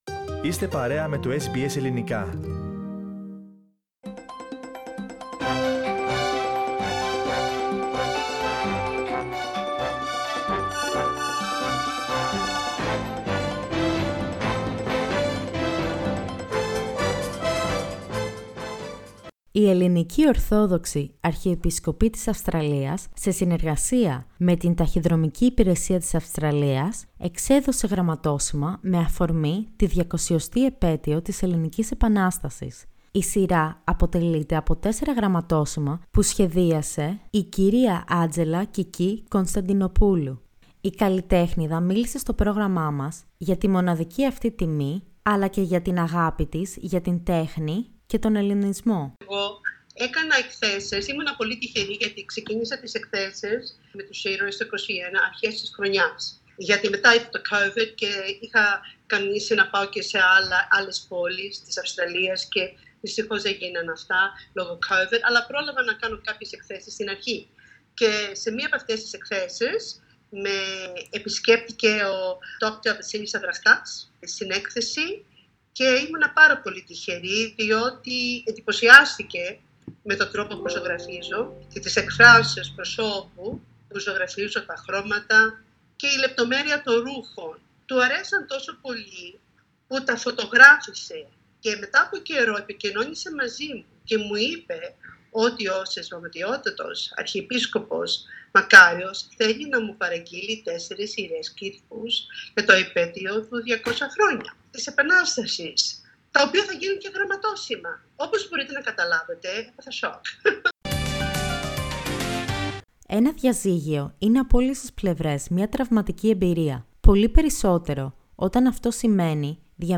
H καλλιτέχνιδα που σχεδίασε γραμματόσημα που απεικονίζουν ηρωικές προσωπικότητες της Ορθόδοξης Εκκλησίας από την Ελληνική Επανάσταση, ο δικηγόρος οικογενειακού δικαίου που μιλά για τον διαμοιρασμό περουσιακών στοιχείων και ο ομογενής που μεγάλωσε σε milk- bar, ήταν μερικά από τα πρόσωπα που μας μίλησαν την περασμένη εβδομάδα.